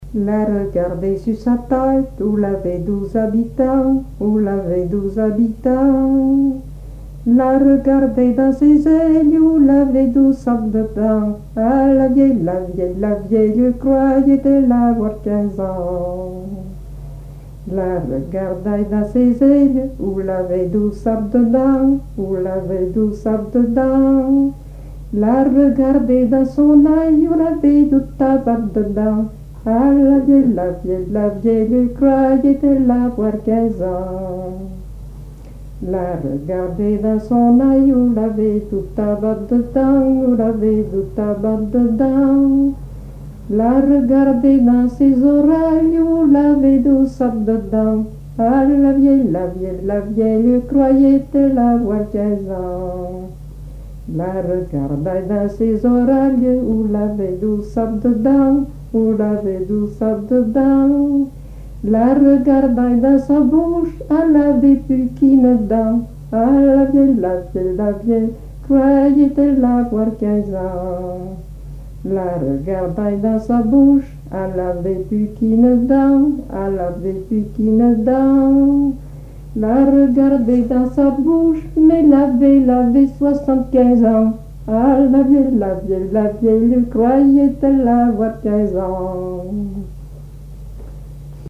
Mémoires et Patrimoines vivants - RaddO est une base de données d'archives iconographiques et sonores.
Genre laisse
Pièce musicale inédite